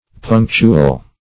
Punctual \Punc"tu*al\, a. [F. ponctuel (cf. Sp. puntual, It.